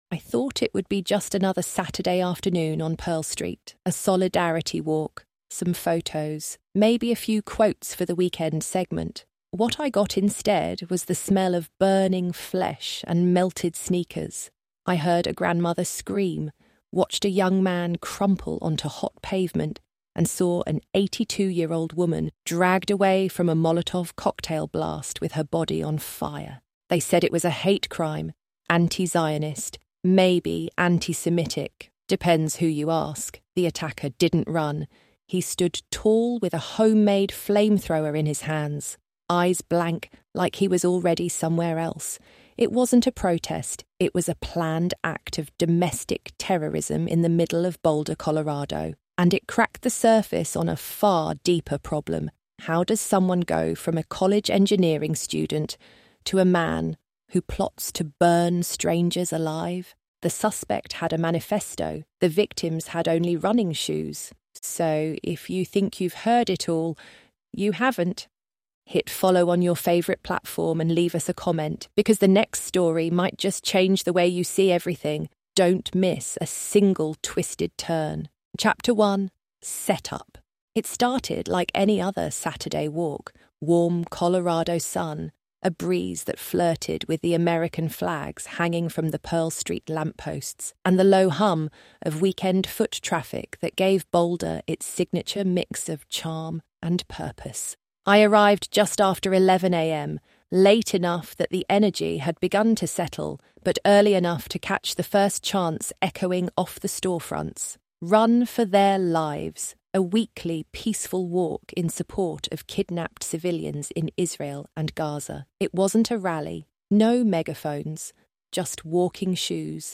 Told by a journalist on the ground, this investigative true crime story reveals the hidden trail of radicalization, psychological breakdown, and a justice system walking the edge of collapse.